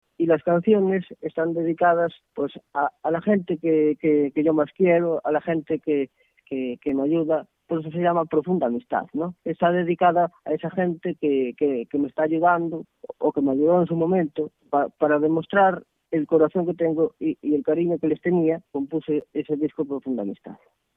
Así que el sueño se materializa: “mi pasión es la música y voy a luchar por conseguirlo. Luchando, luchando... aquí estoy ahora formato MP3 audio(0,18 MB), comenta risueño, al otro lado del hilo telefónico, dedicándonos un alto en el camino de su gira para compartir con nosotros emociones, sentimientos a flor de piel y el deseo, que atisba cada vez más cercano, de alcanzar la gloria del artista con mayúsculas.